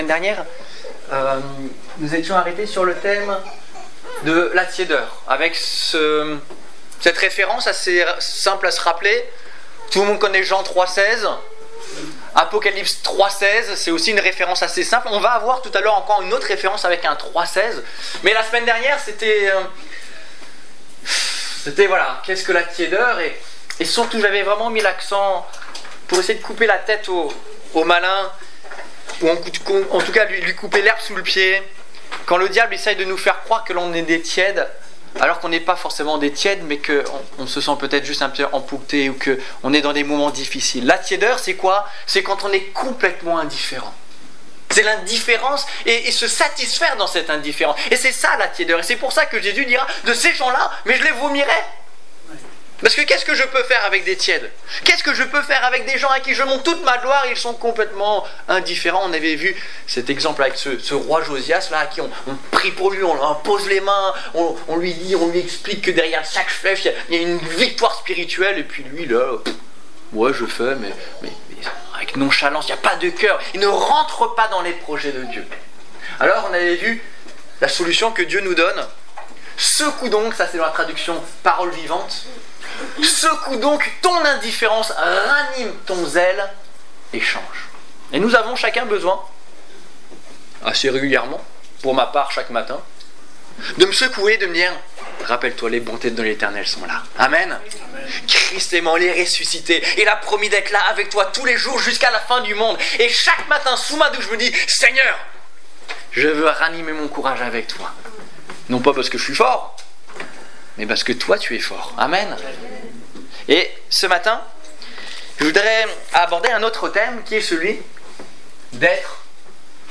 Être bien rempli Détails Prédications - liste complète Culte du 8 février 2015 Ecoutez l'enregistrement de ce message à l'aide du lecteur